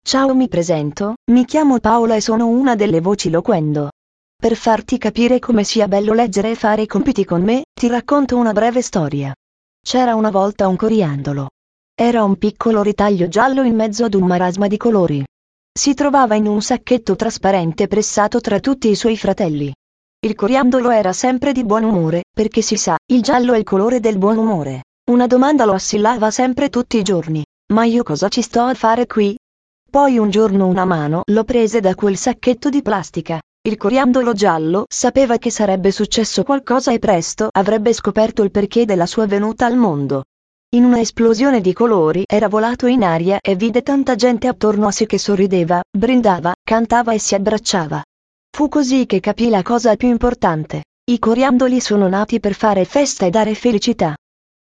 Per questo motivo, la voce risulta estremamente gradevole, chiara e fluente e priva del timbro metallico tipico delle voci artificiali.
Voce italiana Paola
Paola-normale.mp3